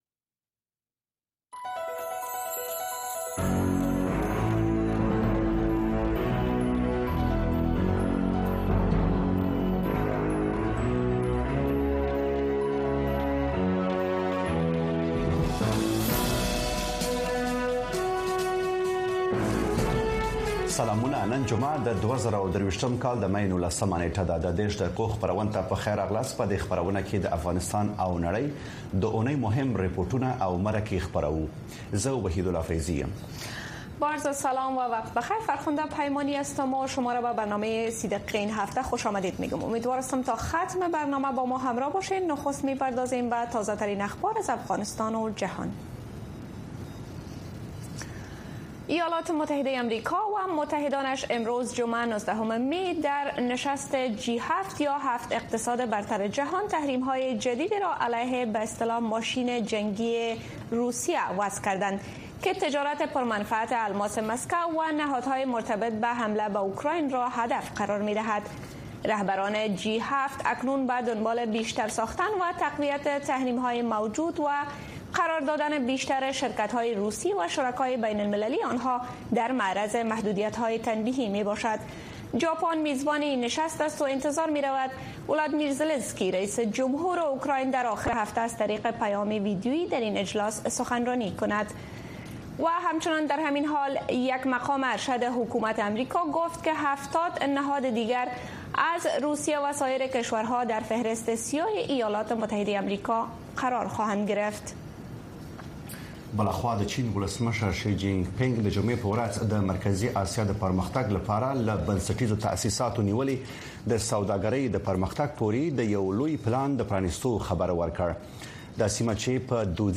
د ۳۰ دقیقې په اونیزه خپرونه کې د اونۍ مهمو پیښو، رپوټونو او مرکو ته بیاکتنه کیږي او د افغانستان د ورځنیو پیښو په اړه تازه او هر اړخیرې ارزونې وړاندې کیږي.